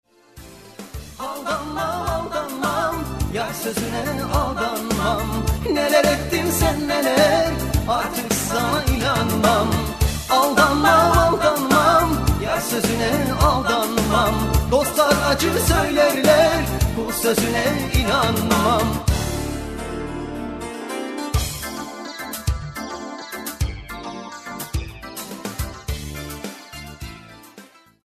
Genre : Pop music